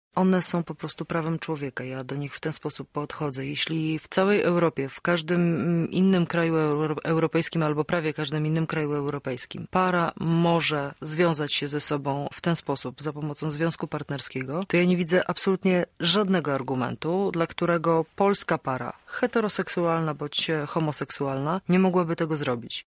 Jedną z najważniejszych obietnic Koalicji jest deklaracja dotyczący legalizacji związków partnerskich niezależnie od orientacji seksualnej. O tym postulacie mówi posłanka Joanna Mucha: